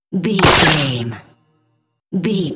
Dramatic hit bloody laser
Sound Effects
heavy
intense
dark
aggressive
hits